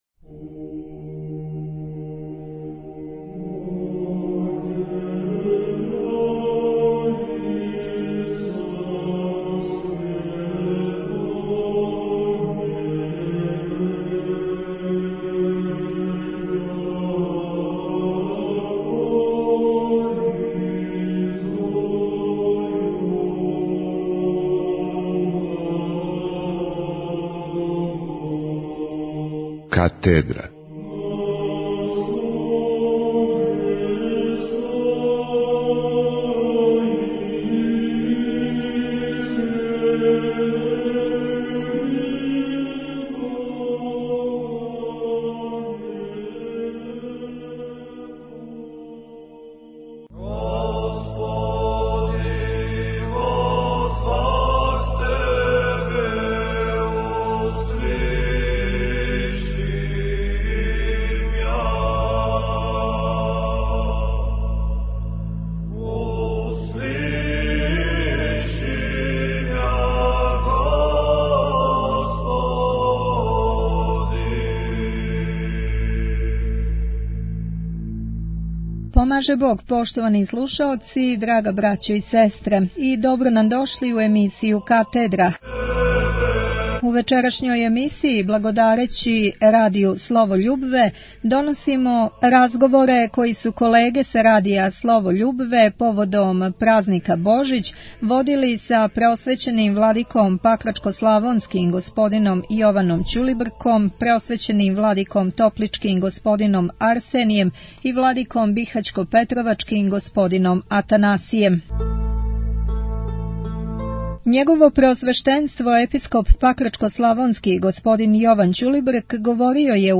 Божићни разговори
У емисији "Катедра" благодарећи Радију "Слово љубве", доносимо божићне разговоре које су новинари овог Радија водили са Преосвећеном господом Епископима Пакрачко-славонским Јованом (Ћулибрком), Бихаћко-петровачким Атанасијем и Топличким Арсенијем